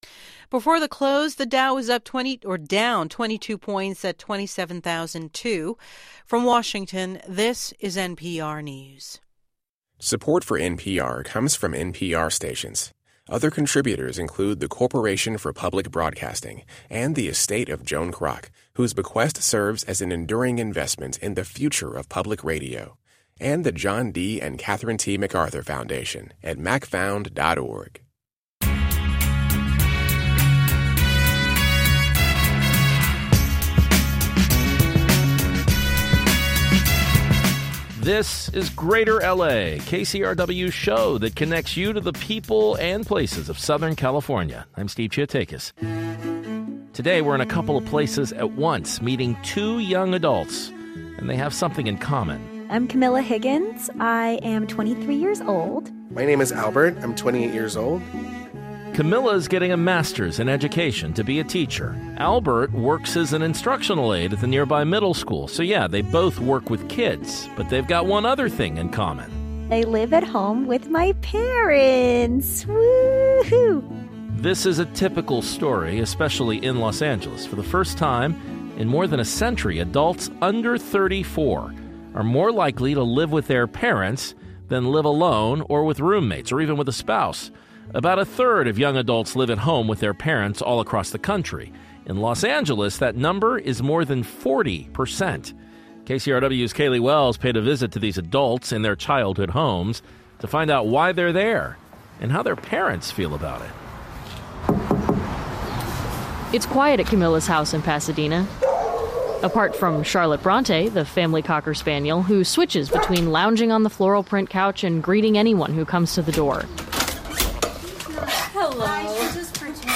But in Los Angeles, it’s more than 40%. We hear from two local educators on why they choose to live with their parents.